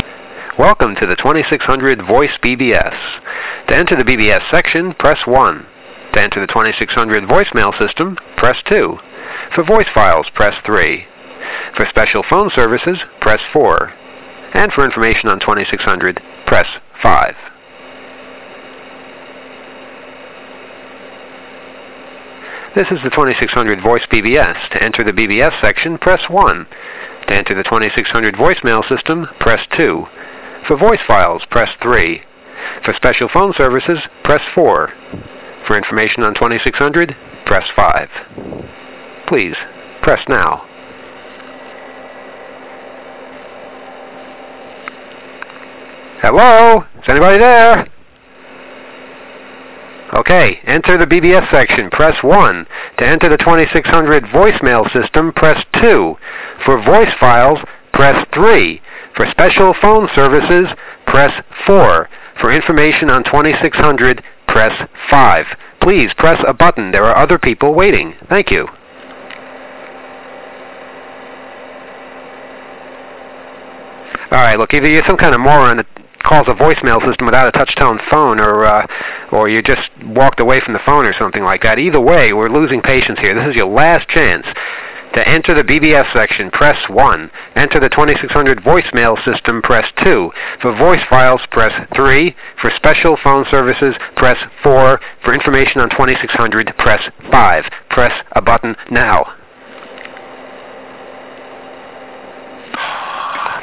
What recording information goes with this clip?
In addition, we’re including all of the system prompts that were recorded by the people of 2600, as we finally lived a dream: to run our own voicemail system.